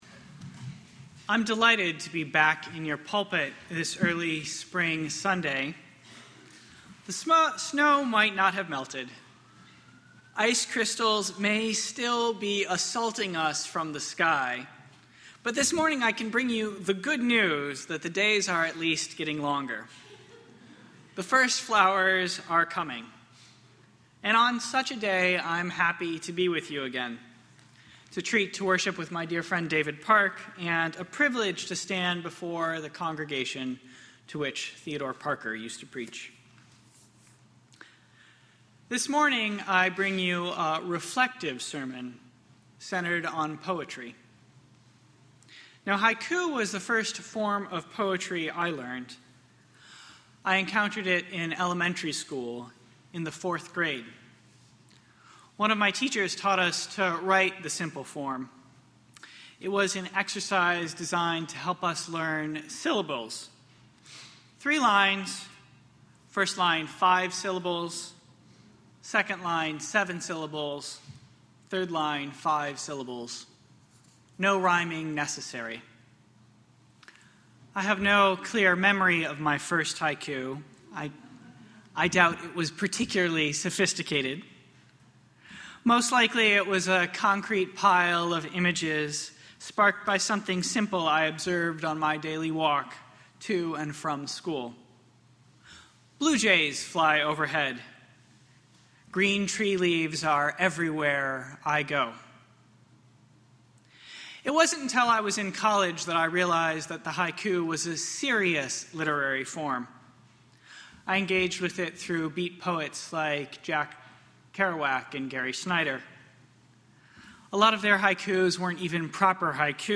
The spiritual discipline of pilgrimage is something we can practice in our daily lives. This service